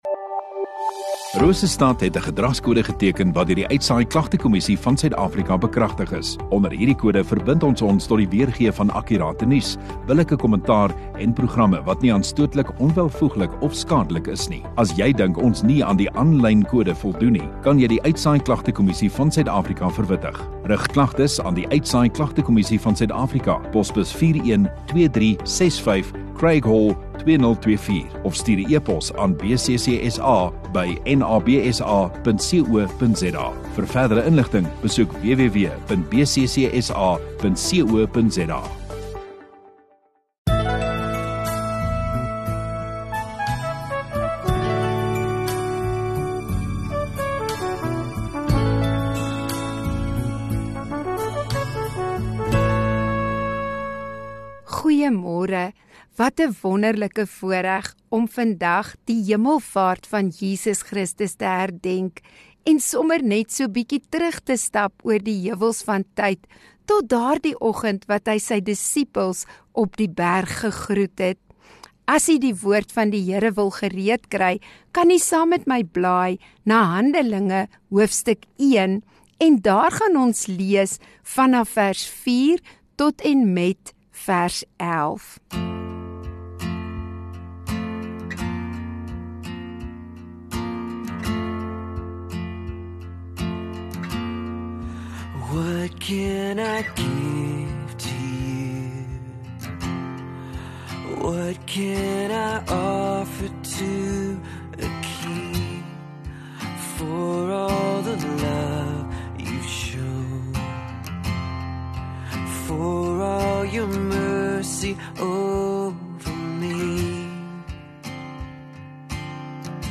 9 May HEMELVAART Donderdagoggend Erediens